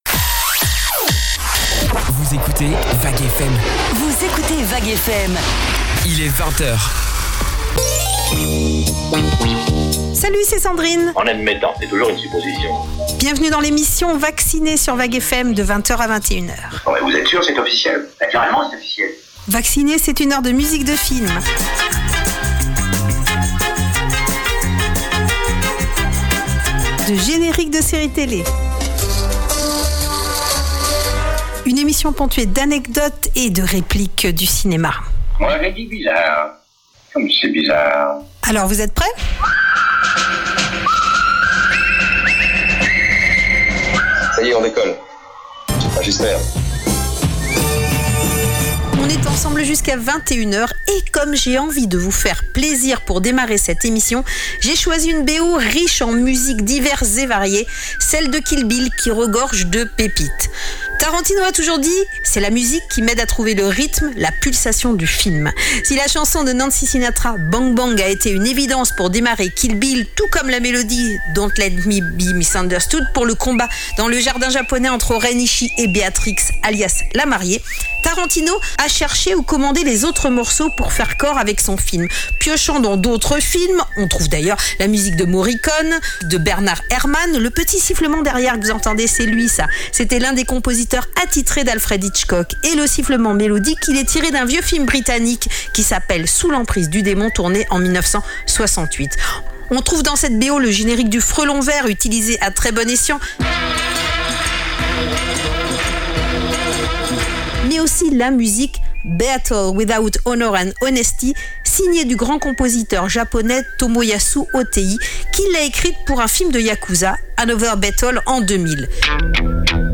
Vous aimez les musiques de films ? les génériques de séries télé et de dessins animés ?